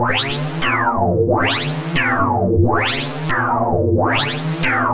PROPHE FX9-S.WAV